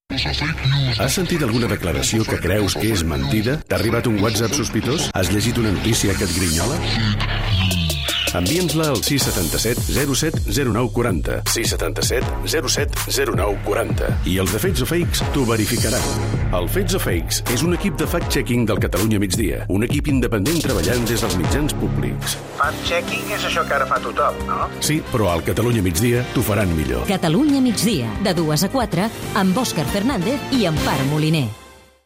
La promoció de la secció del programa "Fets o fakes"